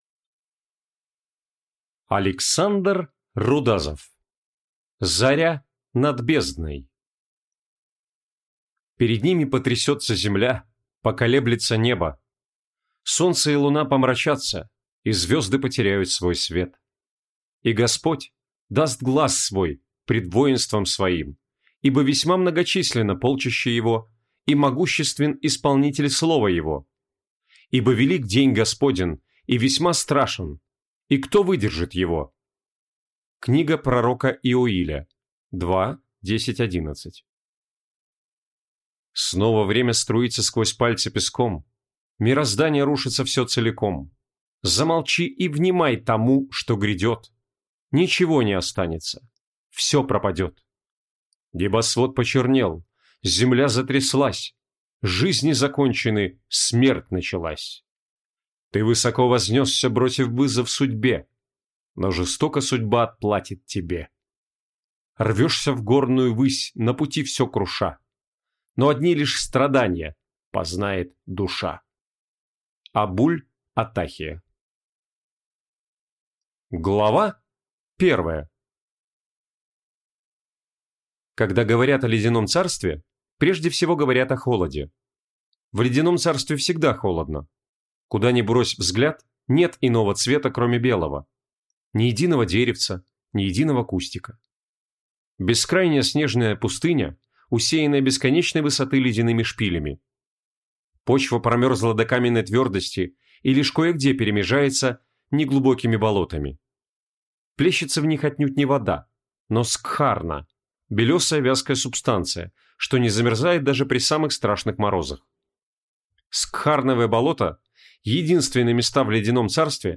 Аудиокнига Заря над бездной | Библиотека аудиокниг
Прослушать и бесплатно скачать фрагмент аудиокниги